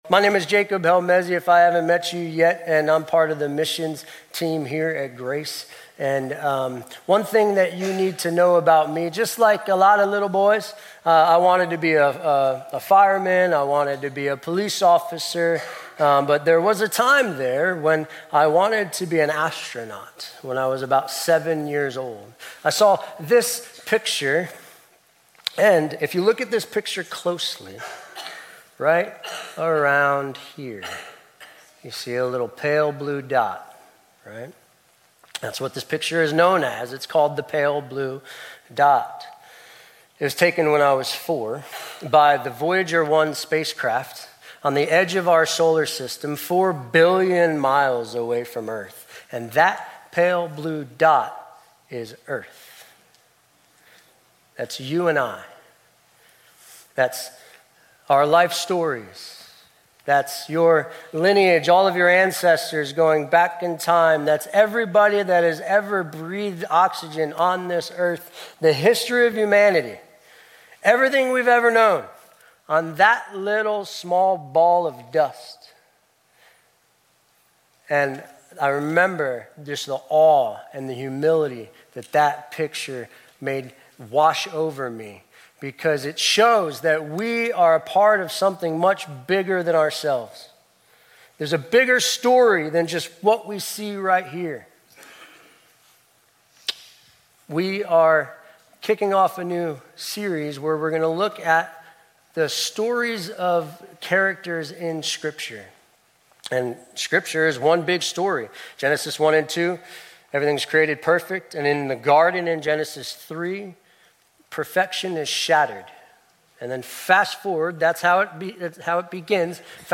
Grace Community Church Old Jacksonville Campus Sermons 6_22 Old Jacksonville Campus Jun 22 2025 | 00:30:07 1x 00:00 / 00:30:07 Subscribe Share RSS Feed Share Link Embed